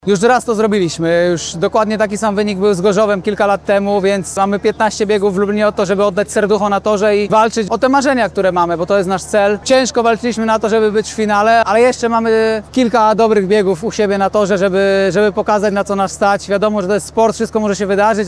– mówi zawodnik zespołu z Lublina
Wypowiedzi-po-finale-w-Toruniu-TORLUB.mp3